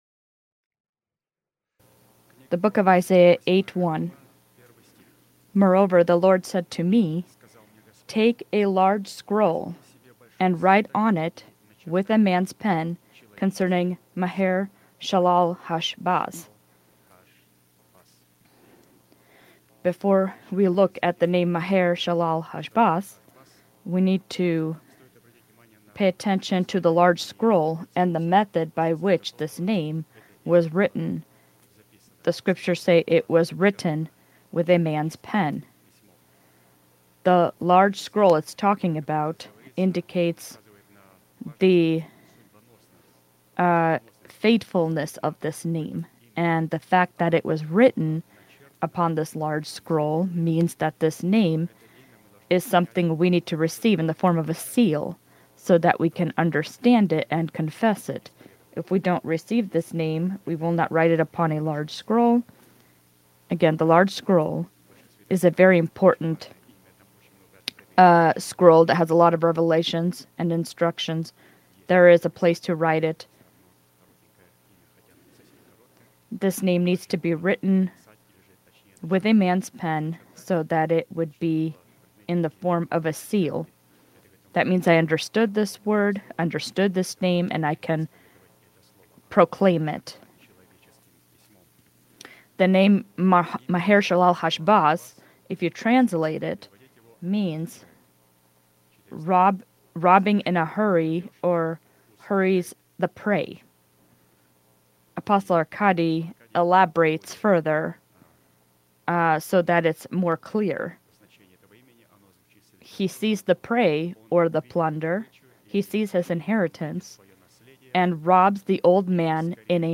Sermon title: Tithes